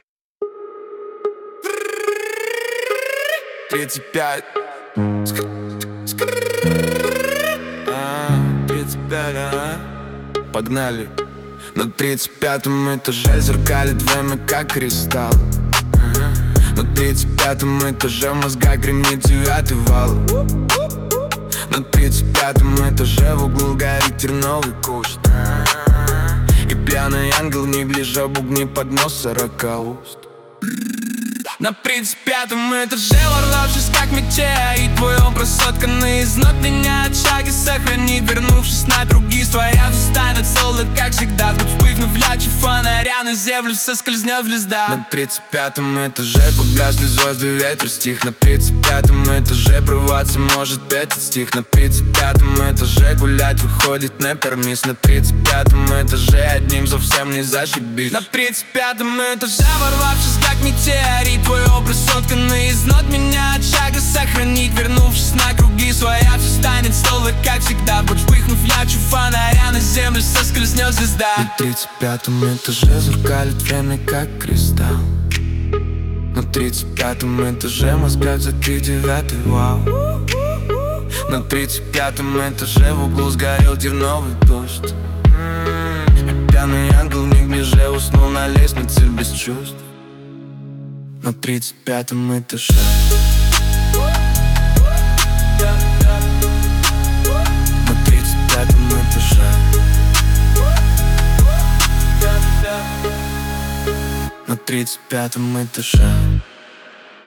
3) Трэп-техно
техно с трэп-перкуссией, быстрый темп, моторный пульс, жёсткий кик, 808-бас как слой, быстрые хэты и дробь, минималистичная но агрессивная аранжировка, гипнотический грув